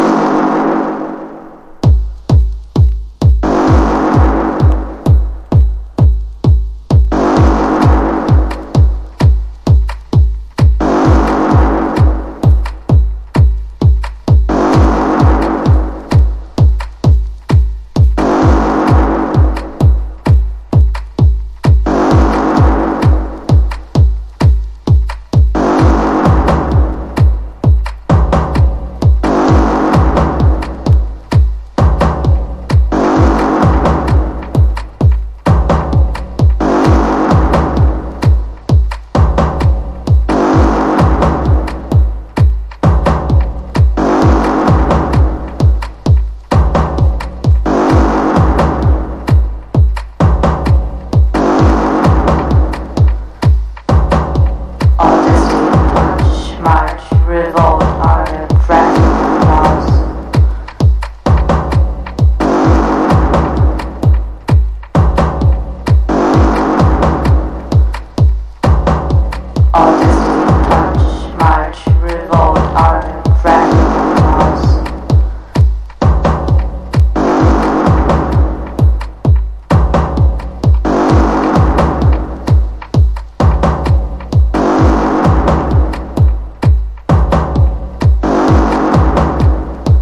ELECTRO HOUSE / TECH HOUSE